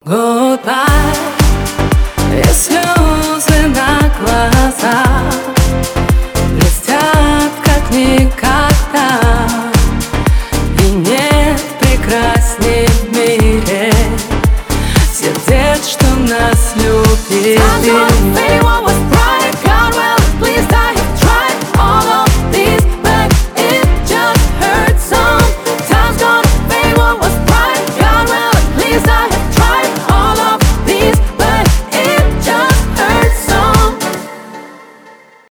танцевальные
поп